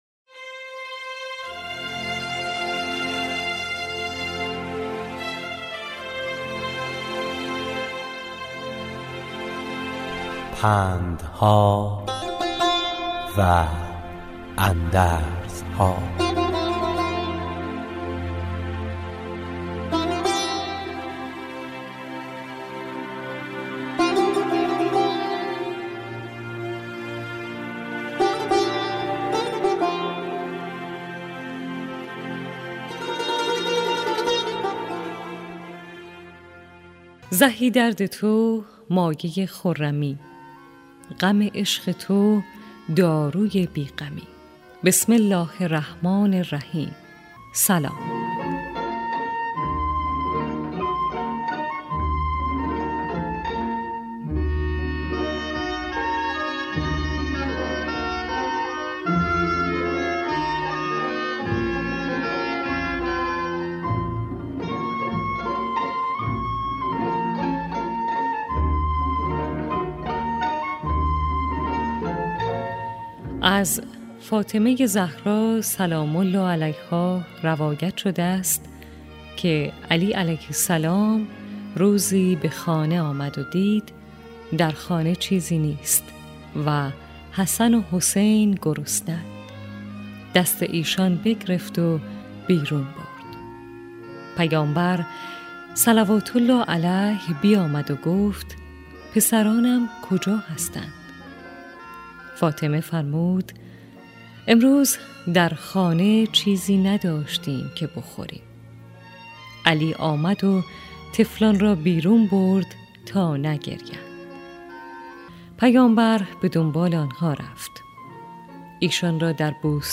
راوی برای شنوندگان عزیز صدای خراسان، حکایت های پندآموزی را روایت می کند